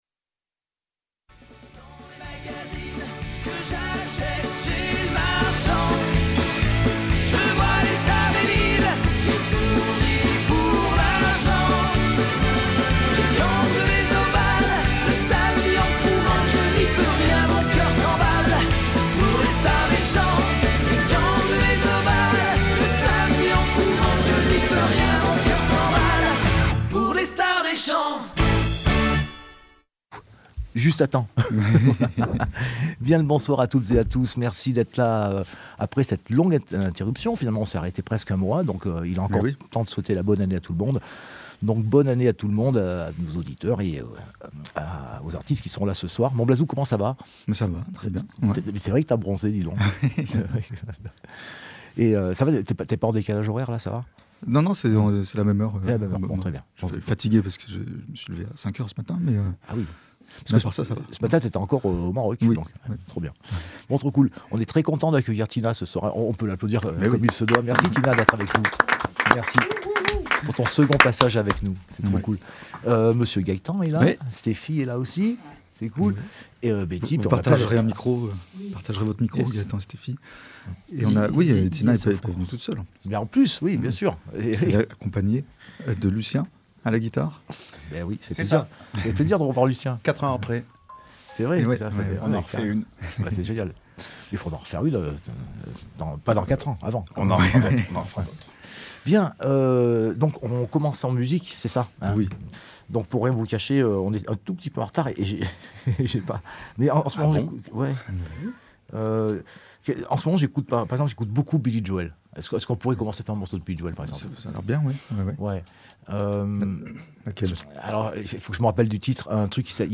Télécharger en MP3 Quelle jolie soirée hier dans Stars Des Champs « L’émission » !